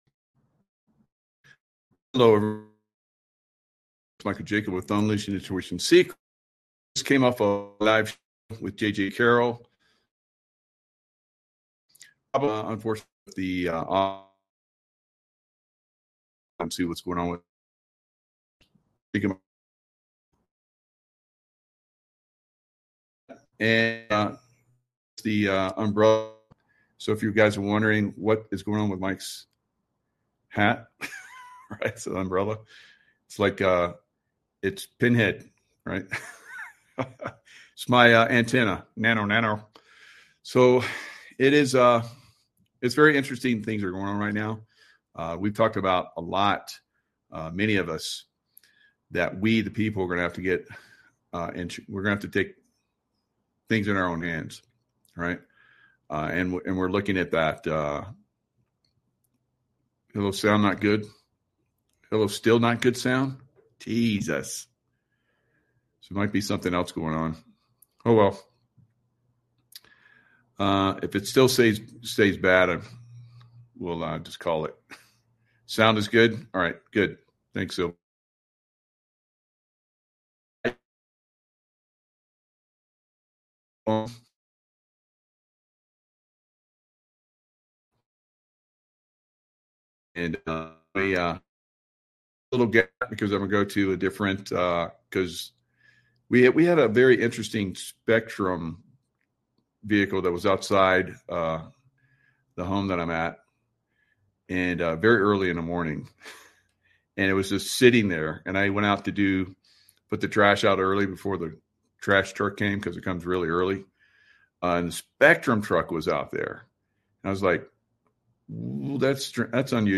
Live Shows